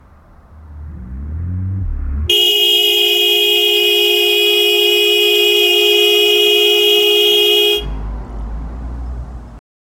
Length 0.3–0.8 seconds. 0:03 Pooping 0:10 baby diarrhea toilet seat 0:44 distorted roar from pain in low quality 0:15 Unpleasant Car horn cluster 0:10 Pooping in toilet but with water 0:15
unpleasant-car-horn-clust-ipeiibaa.wav